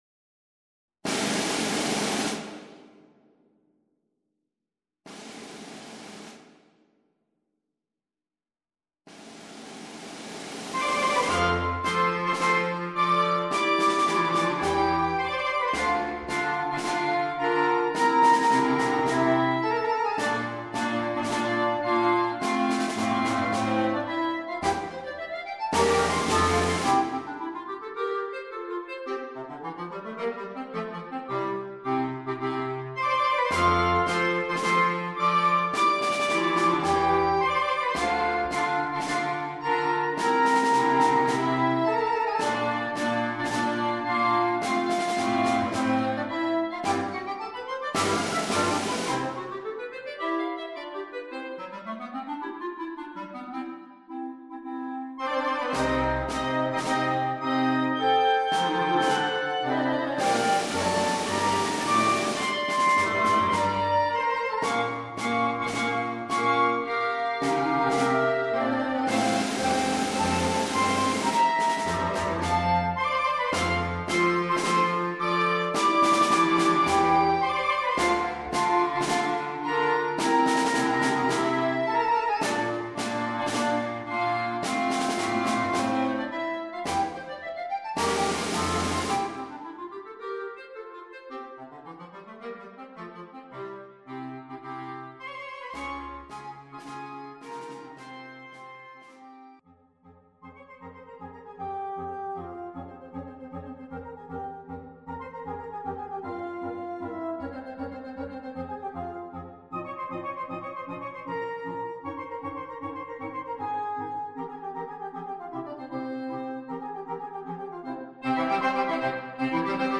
per coro di clarinetti